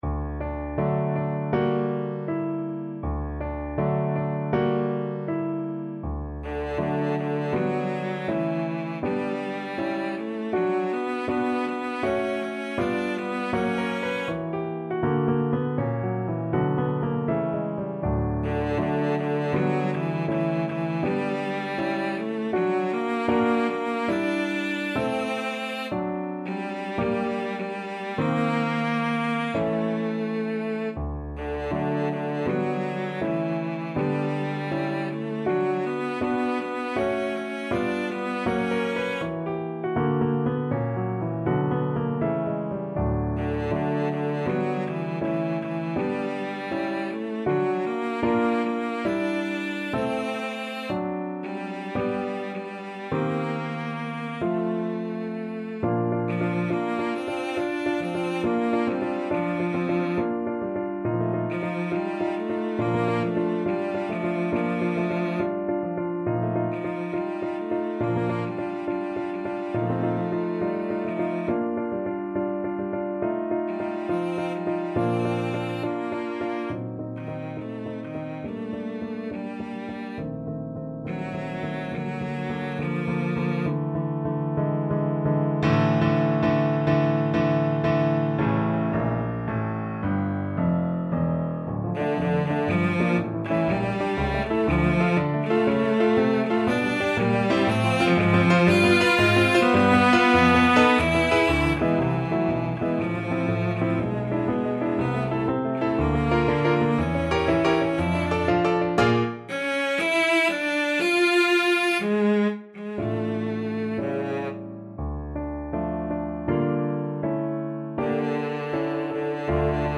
2/4 (View more 2/4 Music)
Moderato =80
Classical (View more Classical Cello Music)